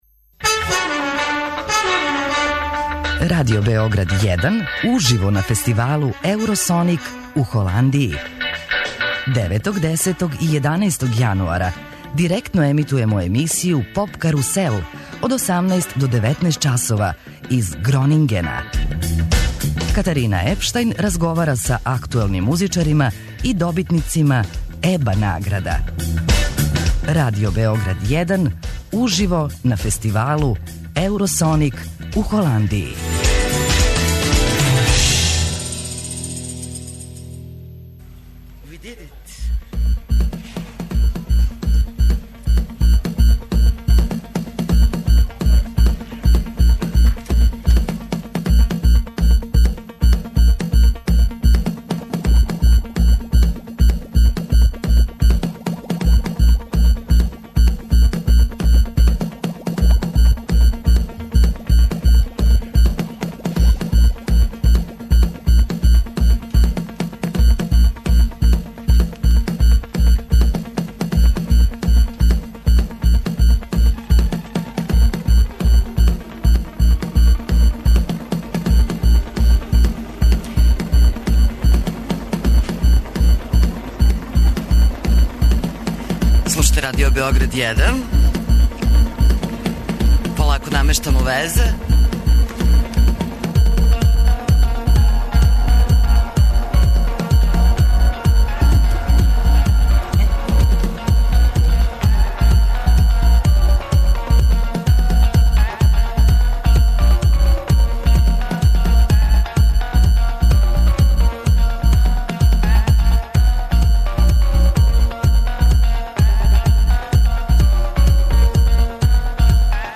Екипа Радио Београда 1, којој ће друштво правити и двоје слушалаца извучених у наградној игри, и ове године реализује директне преносе са Eurosonic фестивала у Холандији. Биће то прилика да премијерно чујемо неке од младих европских састава који ће 'трести' континентом наредних година.